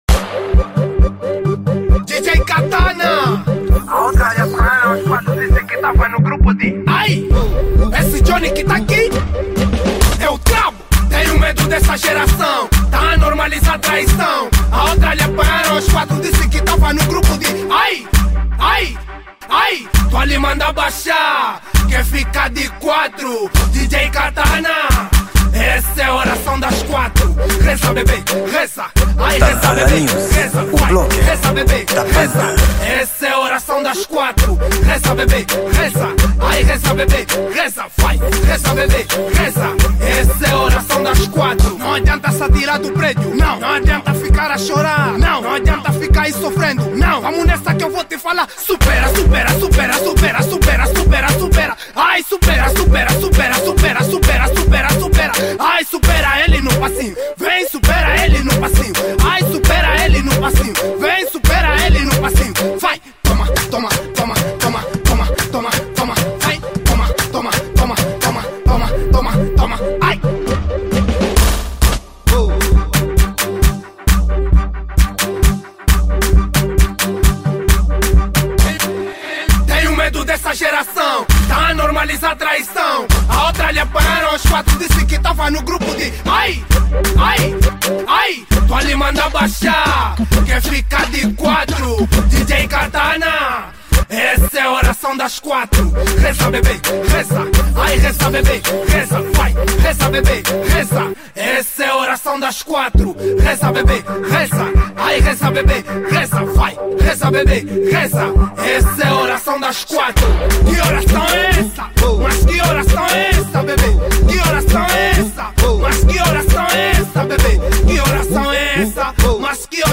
Genero: Afro House